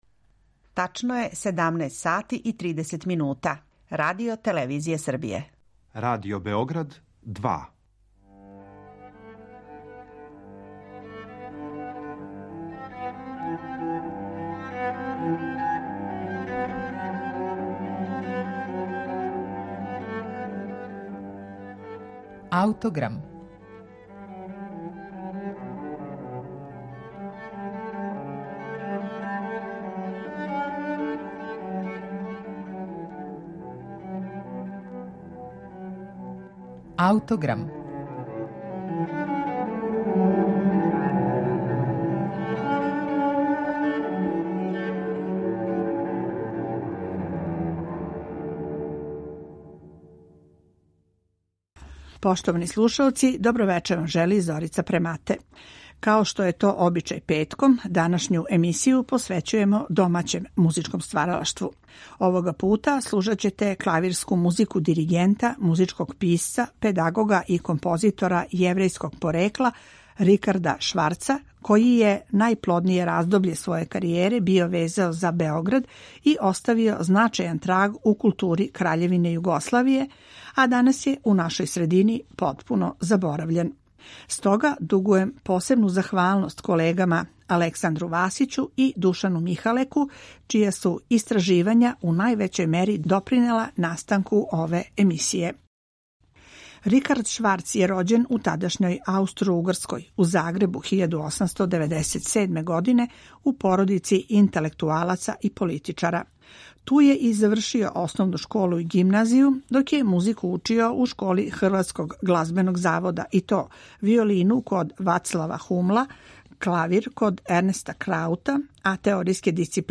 Петком је емисија Аутограм посвећена ауторским поетикама домаћих музичких стваралаца, па ћемо вам представити дела за клавир нашег заборављеног композитора Рикарда Шварца, који је трагично изгубио живот у логору Јасеновац 1942. године.